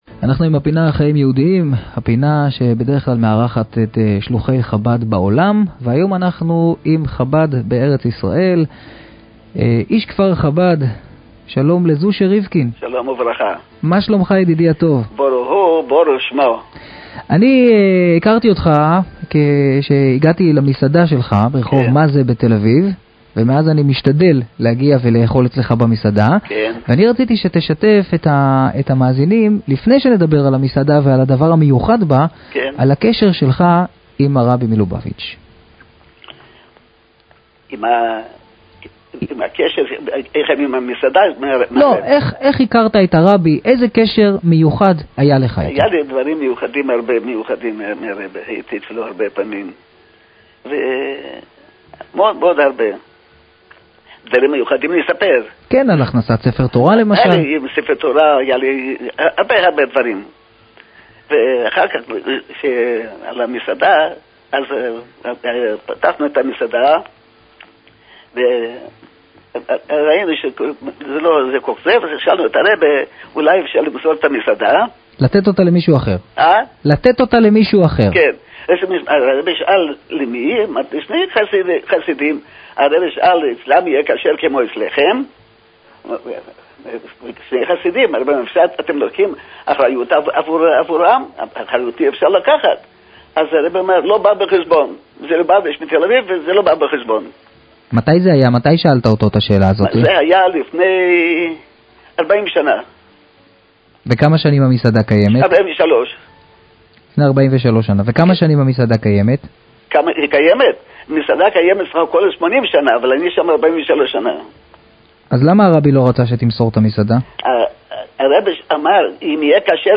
היה אורח הפינה הקבועה של ראיון עם חסיד חב"ד